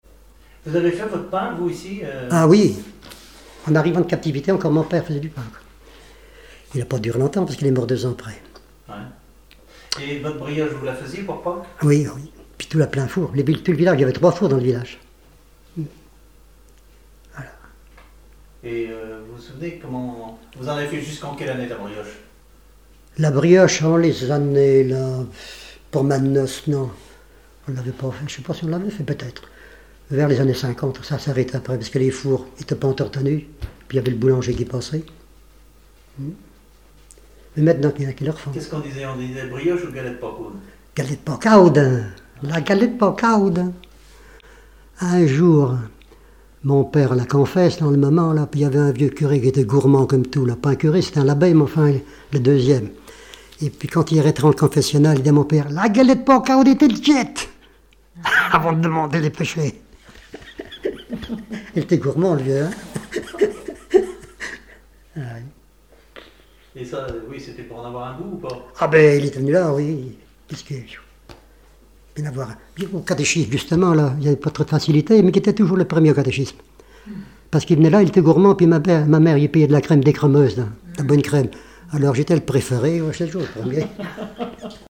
regroupement de chanteurs locaux
Catégorie Témoignage